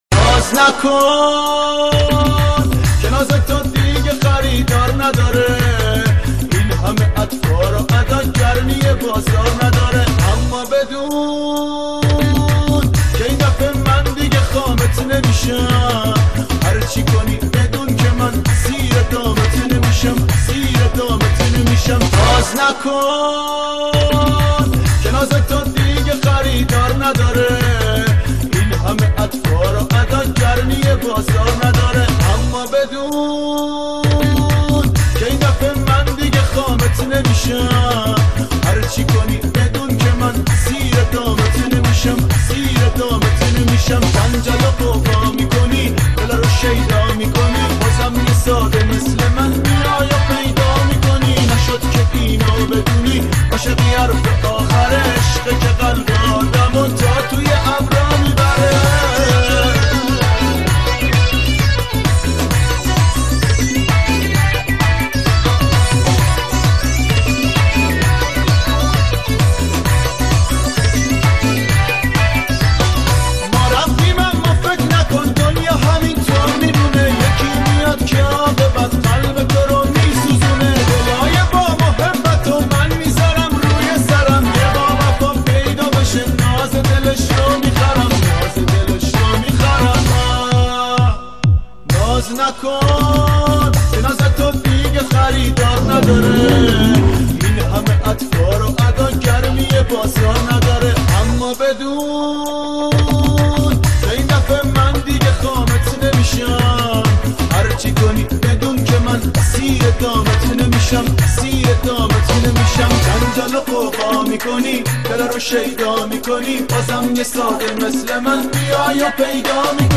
ریمیکس بیس دار تند